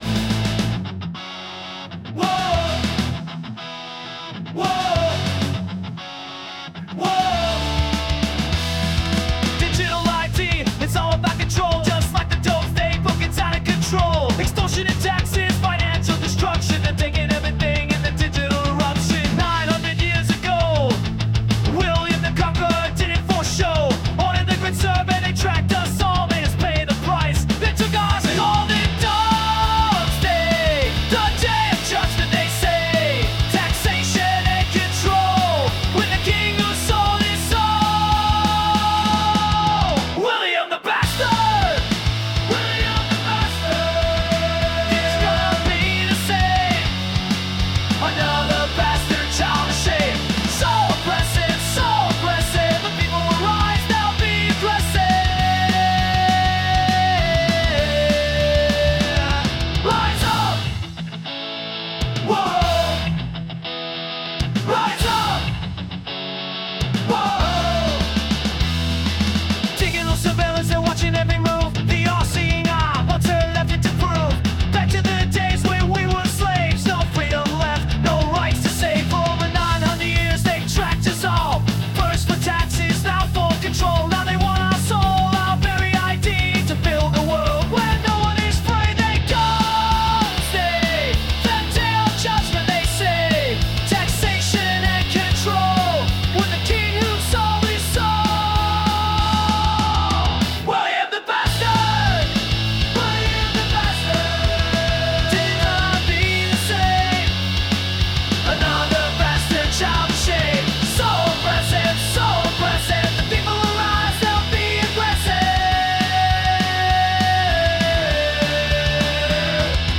PUNK ROCK VERSION